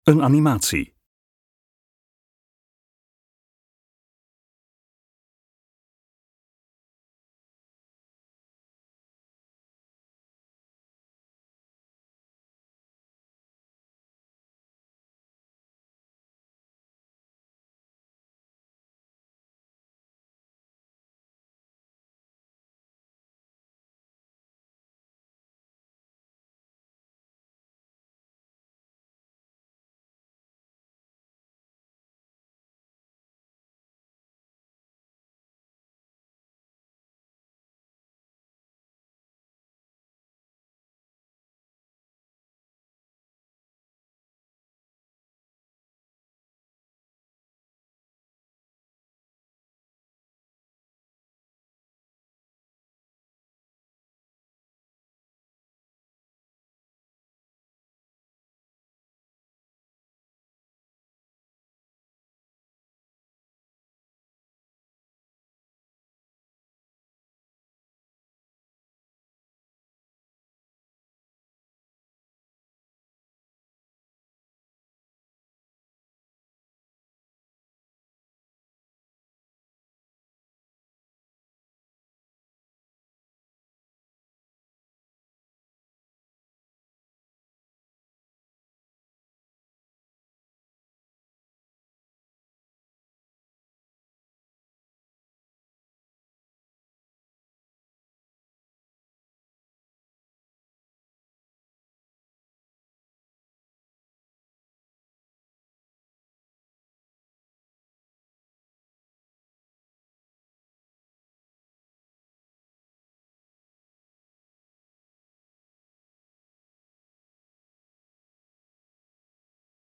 Animatie baggeren in vaarwegen Zuid-Nederland